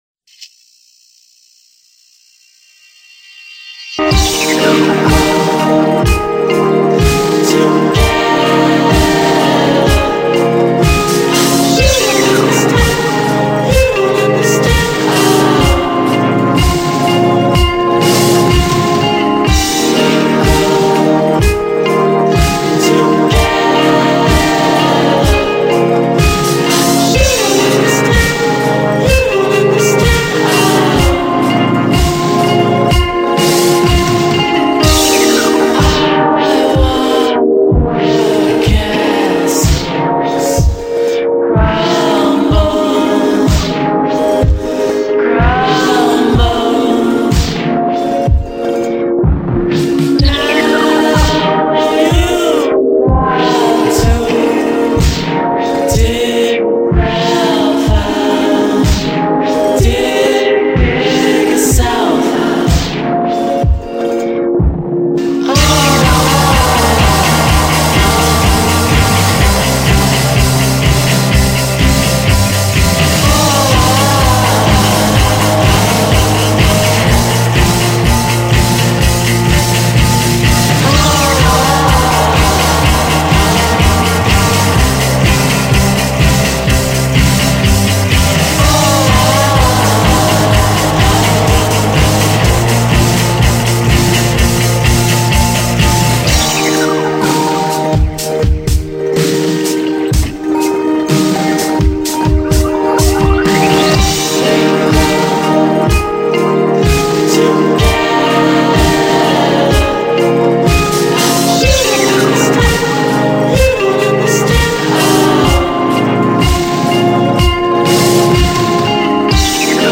psychedelic-pop band